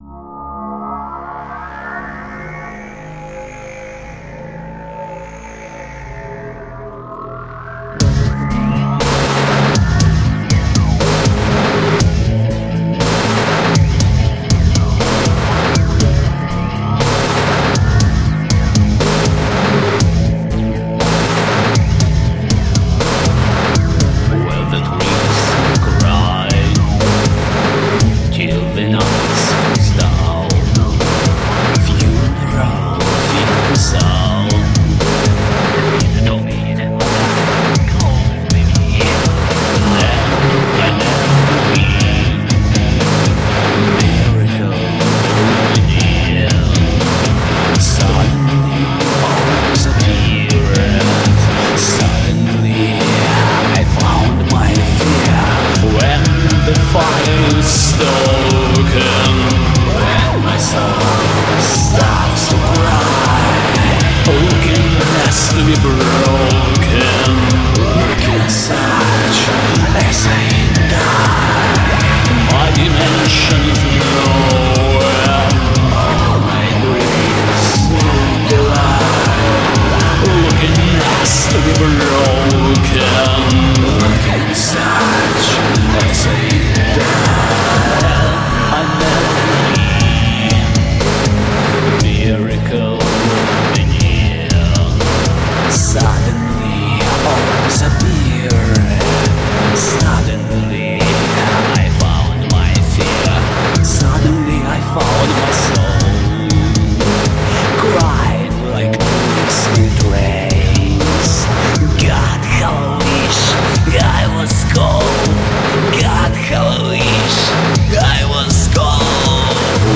dark version